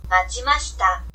ma chi ma shi ta